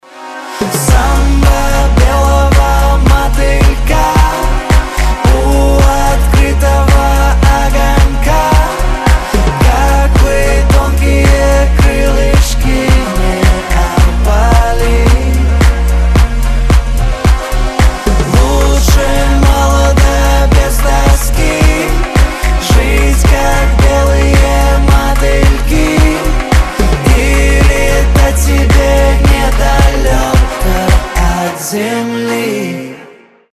• Качество: 256, Stereo
поп
dance
vocal